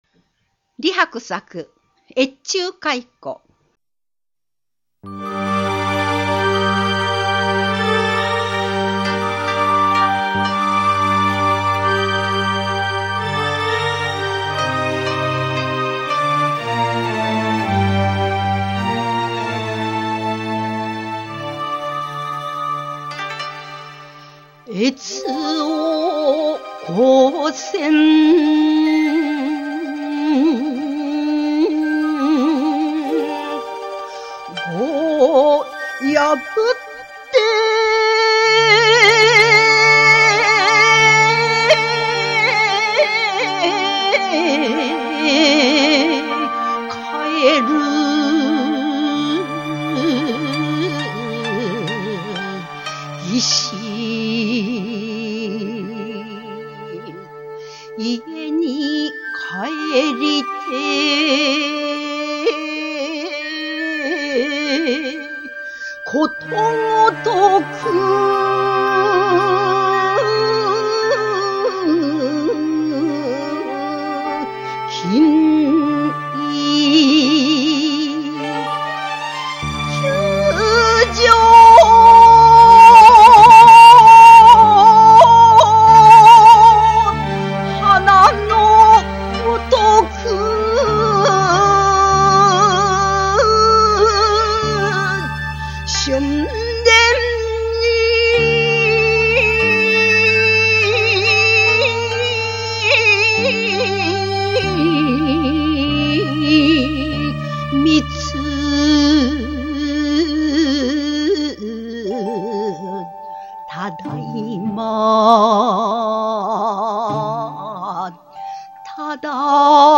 漢詩紹介